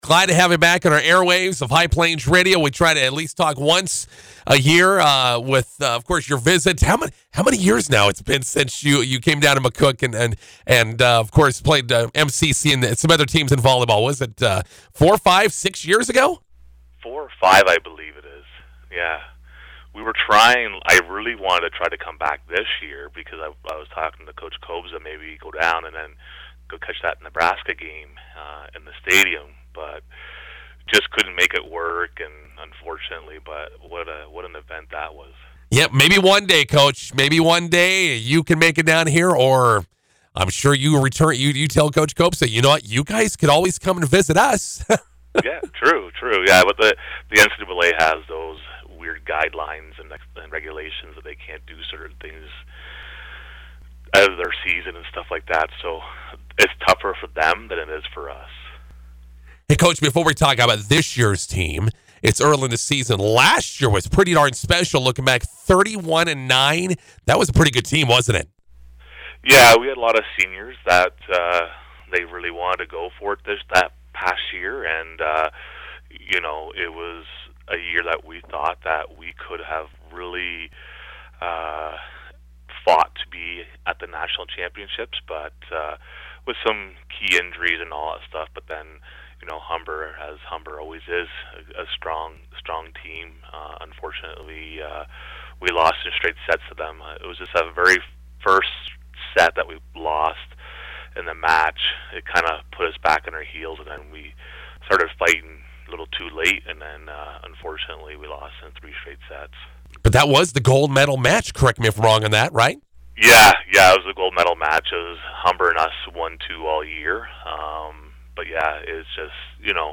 INTERVIEW: Durham Lords Volleyball aiming toward another OCAA championship match appearance.